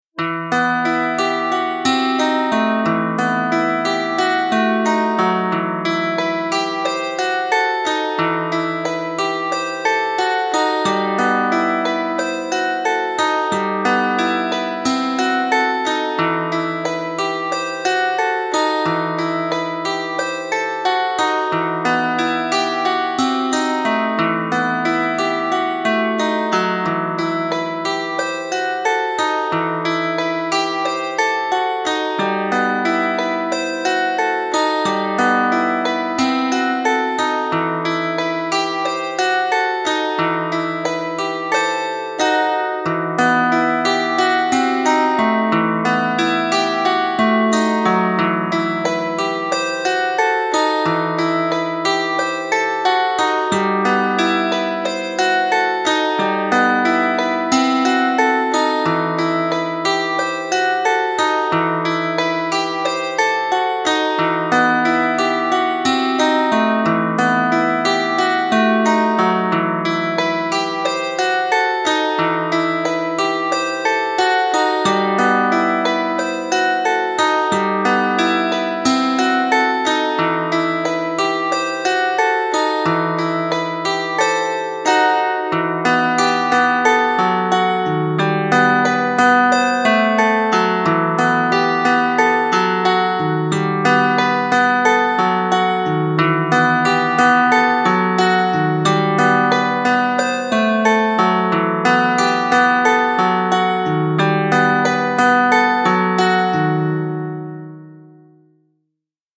DIY Learn a Language - Ukrainian Musical Instruments
Bandura
Bandura .mp3